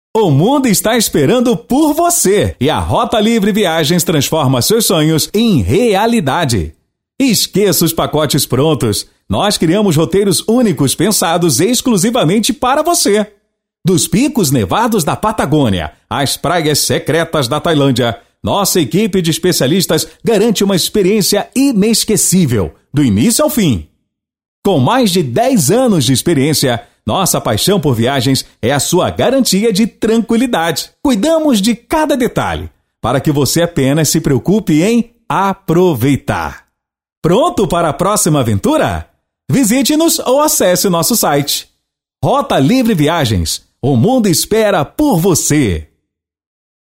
Spot Comercial
Impacto
Animada